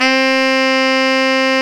SAX TENORF0M.wav